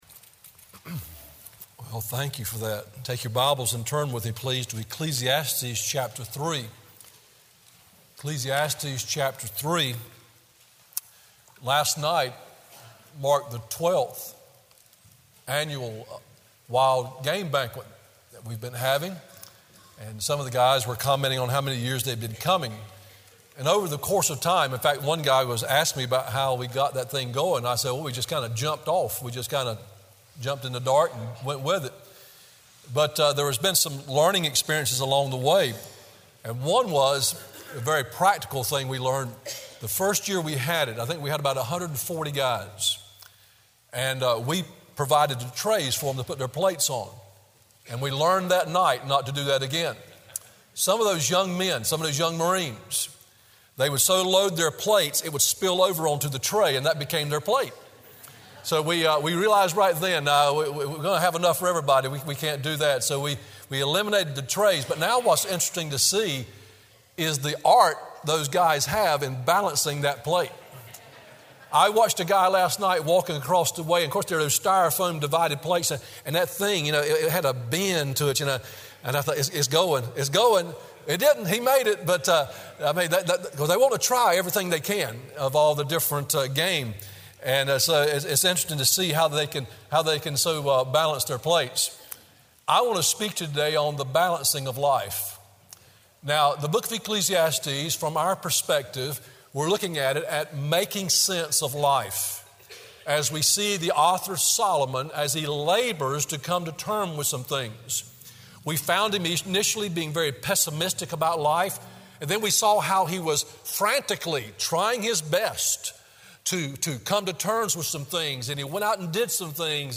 Morning WorshipEcclesiastes 3:9-14